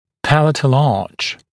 [‘pælətl ɑːʧ][‘пэлэтл а:ч]небная дуга (при усилении опоры), небный бюгель